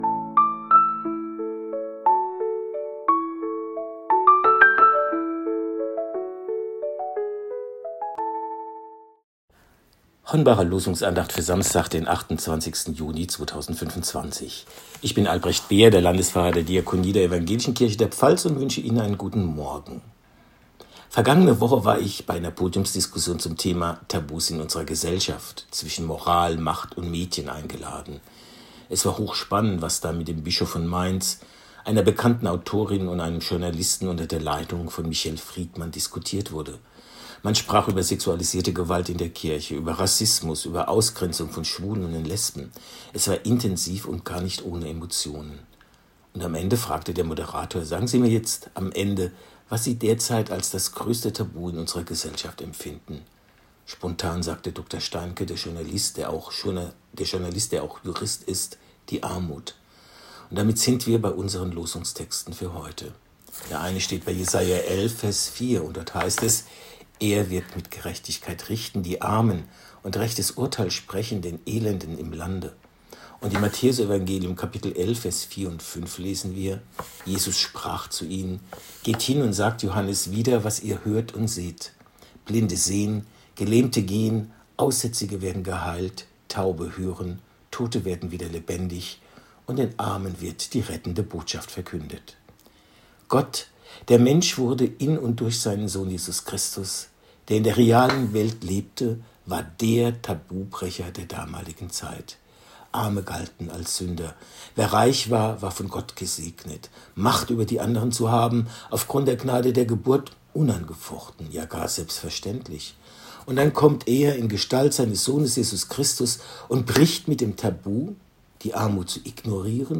Losungsandacht für Samstag, 28.06.2025